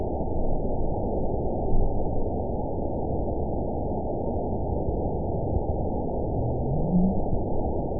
event 920535 date 03/29/24 time 02:05:41 GMT (1 year, 2 months ago) score 9.40 location TSS-AB03 detected by nrw target species NRW annotations +NRW Spectrogram: Frequency (kHz) vs. Time (s) audio not available .wav